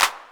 808-Clap11.wav